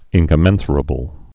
(ĭnkə-mĕnsər-ə-bəl, -shər-)